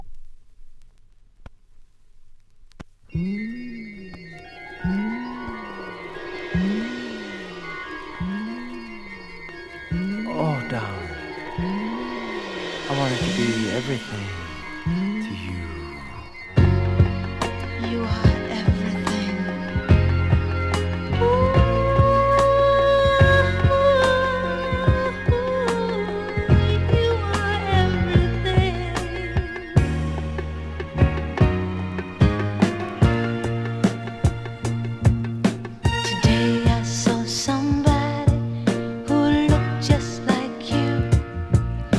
7inch, Vinyl
出だしの何週かノイズありますが、その他は良好ですので試聴で確認下さい（ノイズ分値段下げての出品です）